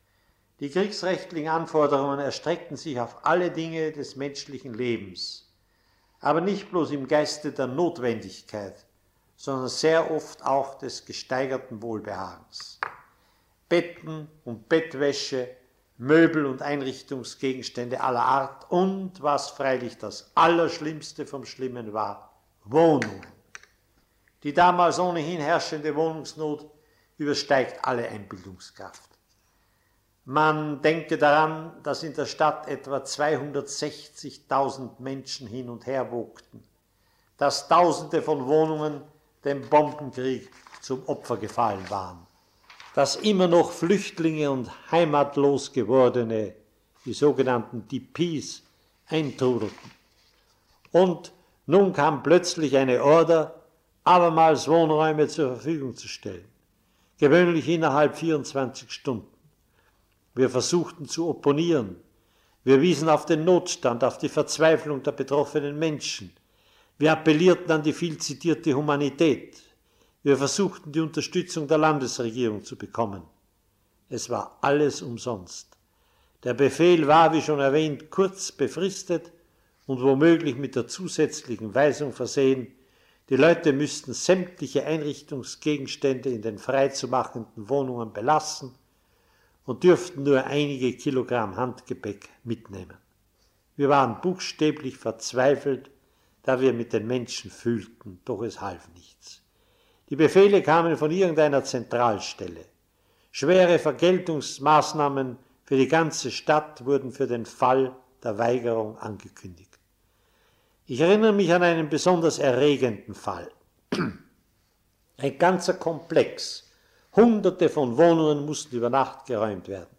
Interview mit Ernst Koref zur Wohnungsnot in Linz